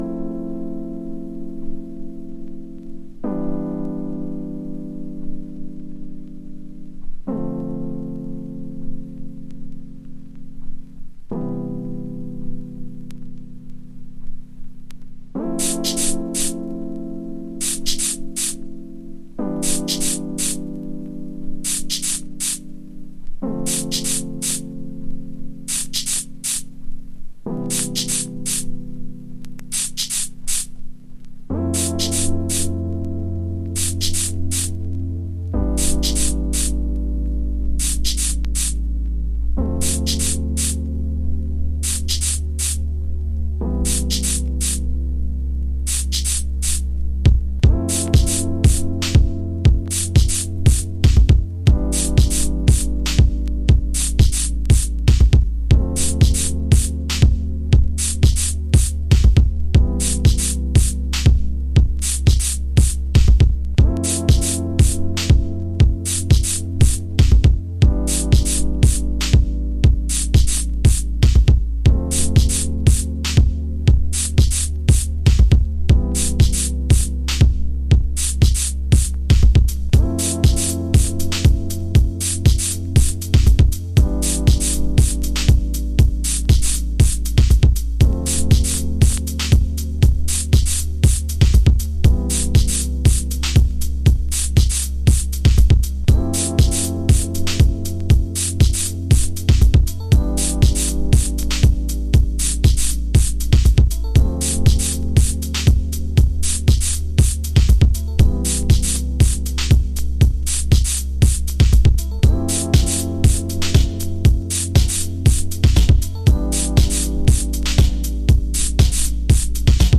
エクスタティックな空間系シンセが揺らめく4トラック。